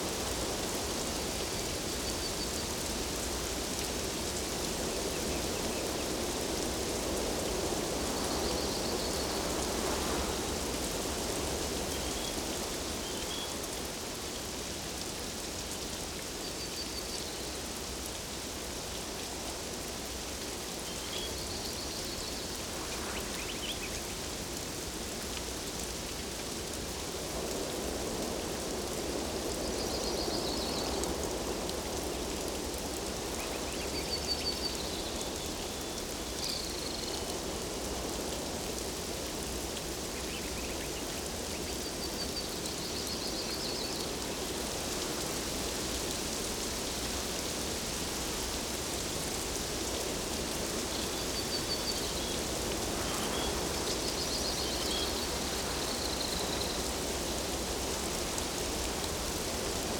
Forest Day Rain.ogg